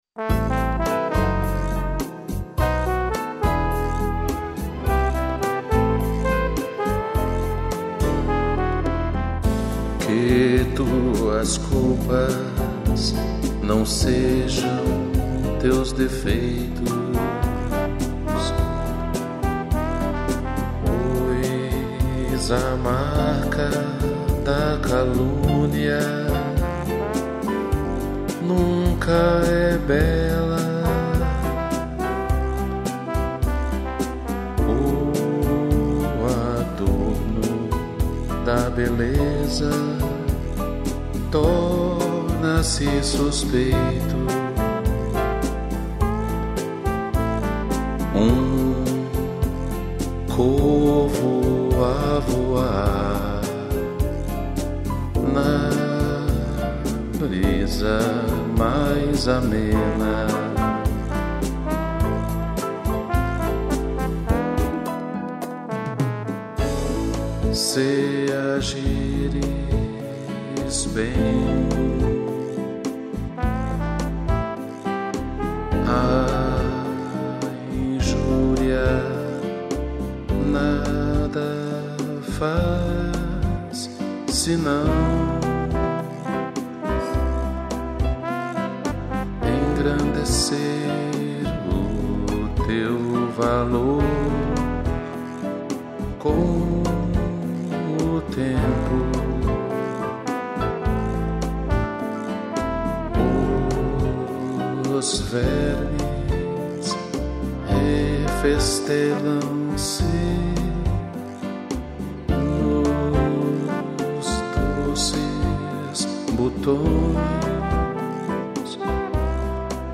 piano e trombone